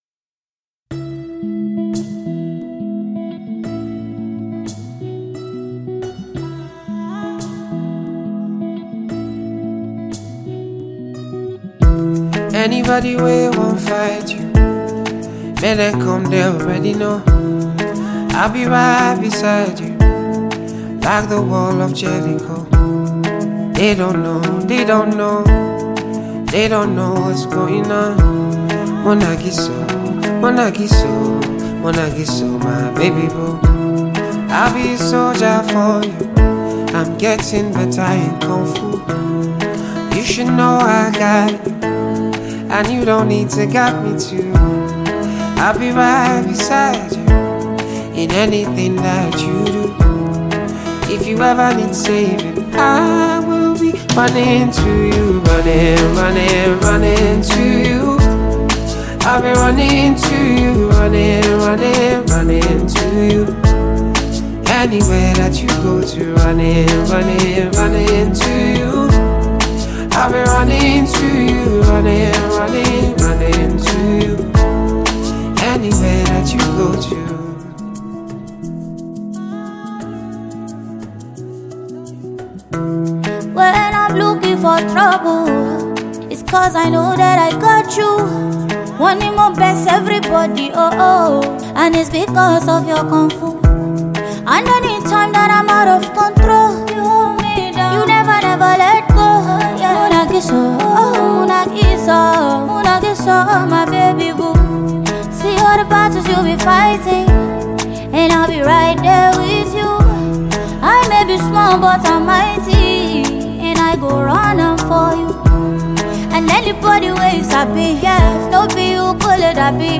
refix